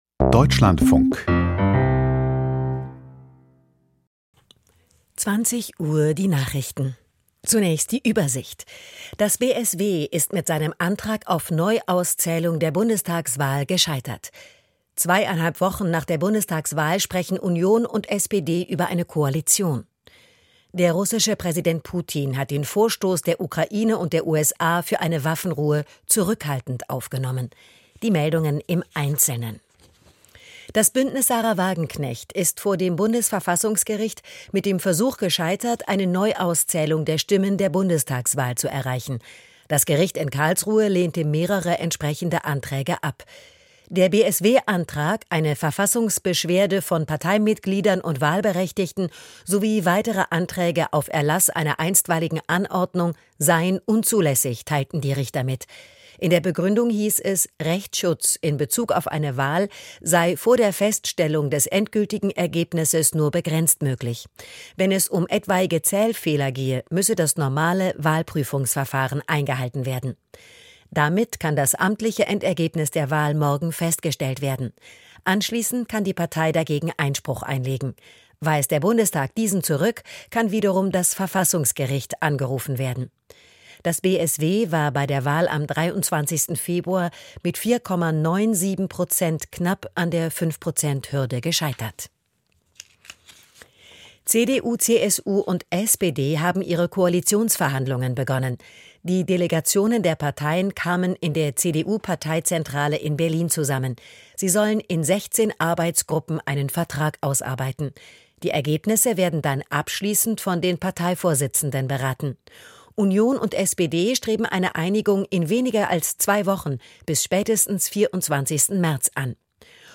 Die Deutschlandfunk-Nachrichten vom 13.03.2025, 20:00 Uhr